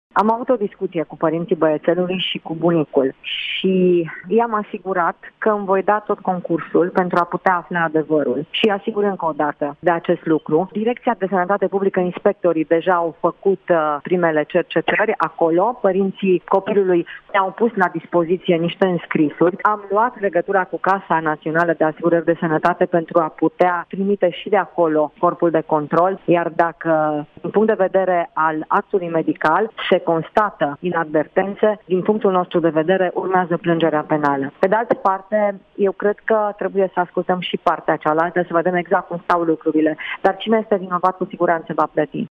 Într-o intervenţie la un post de televiziune, ministrul Sănătăţii, Sorina Pintea, a precizat că Direcţia de Sănătate Publică a început o anchetă în acest caz şi a adăugat că, cei care vor fi găsiţi vinovaţi, vor fi pedepsiţi.